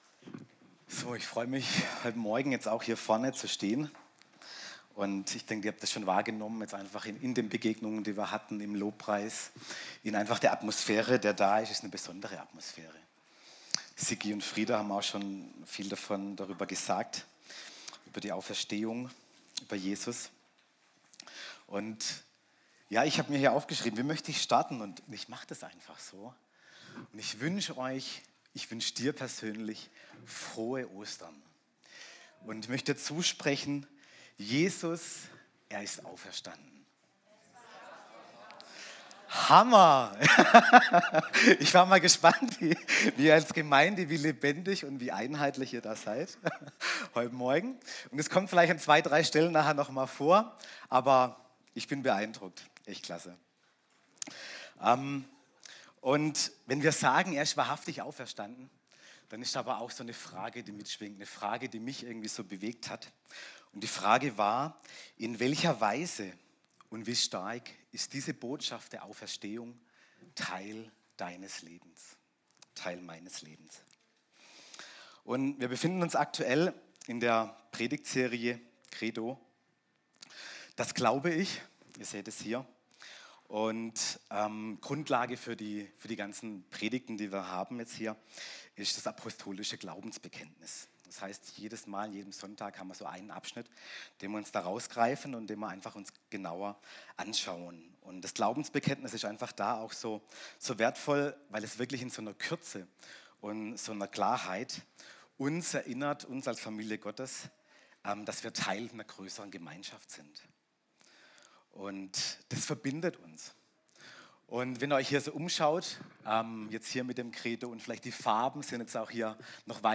Download der Audiodatei  Kategorie Predigten  Kategorie Auferstehung